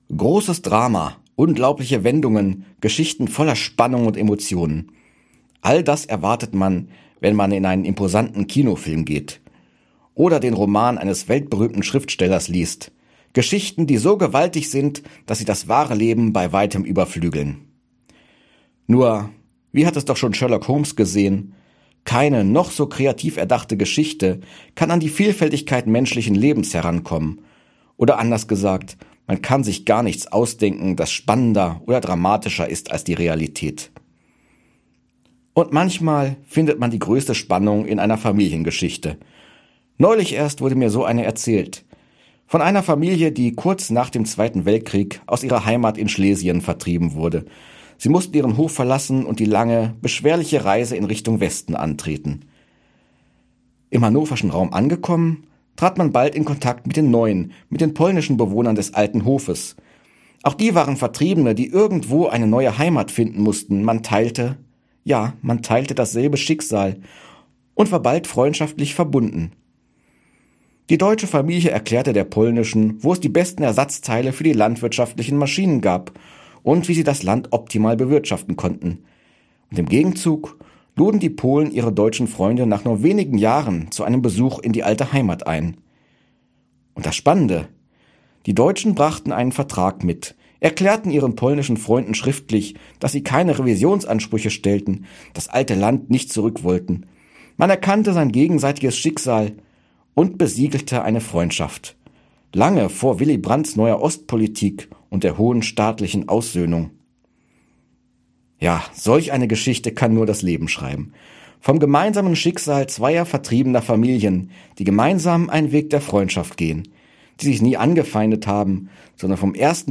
Radioandacht vom 11.